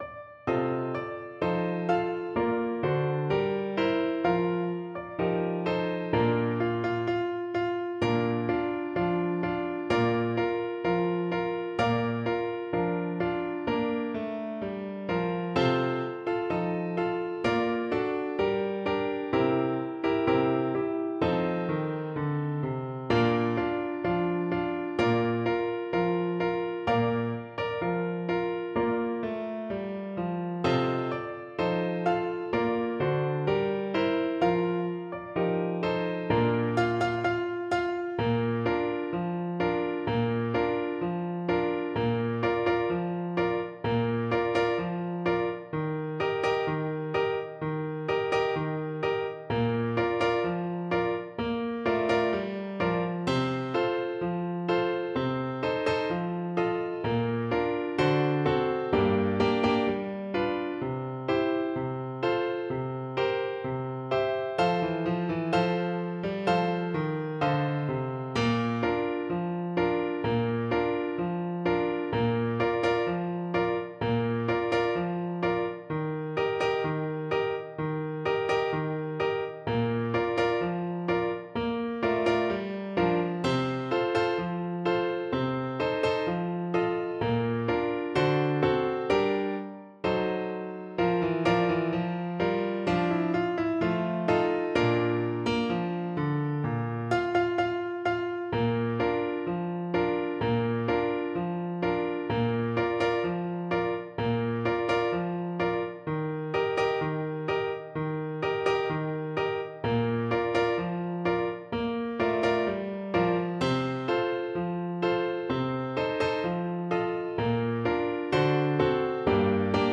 Play (or use space bar on your keyboard) Pause Music Playalong - Piano Accompaniment Playalong Band Accompaniment not yet available transpose reset tempo print settings full screen
Tempo di Marcia = c.86
Bb major (Sounding Pitch) C major (Clarinet in Bb) (View more Bb major Music for Clarinet )